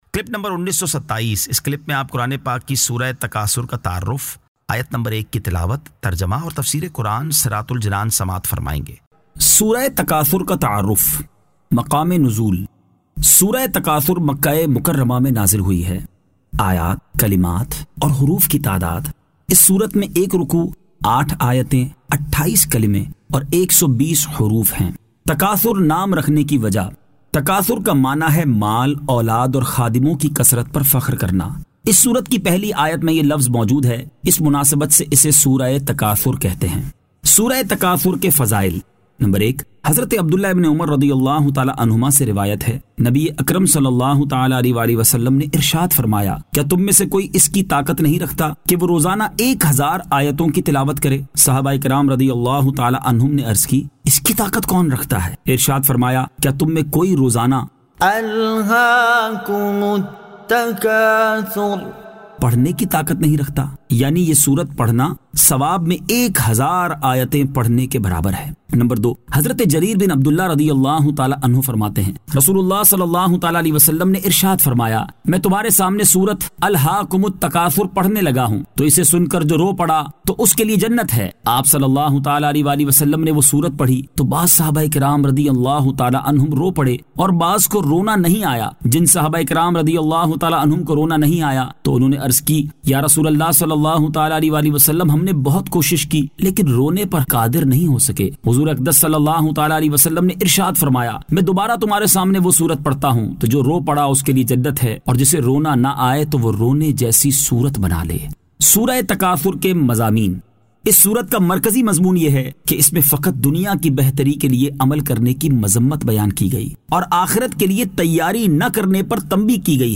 Surah At-Takathur 01 To 01 Tilawat , Tarjama , Tafseer